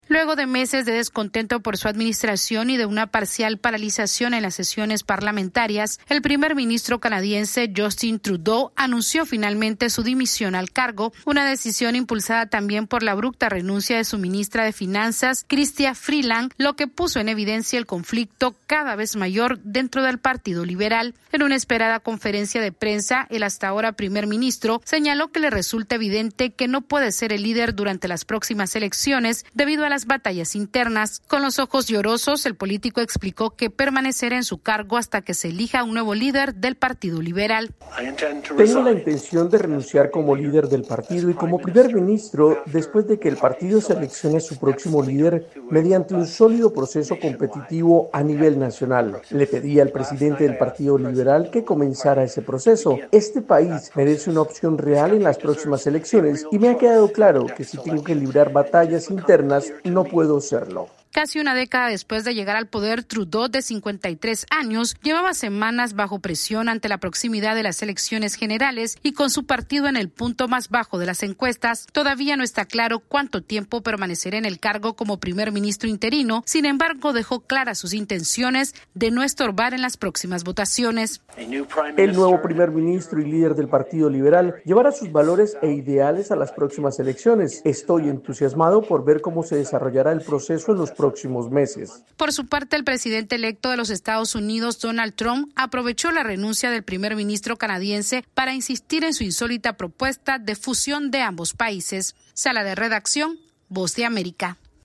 El primer ministro de Canadá Justin Trudeau anunció su dimisión tras casi 10 años en el poder, ante el creciente descontento sobre su liderazgo. Esta es una actualización de nuestra Sala de Redacción...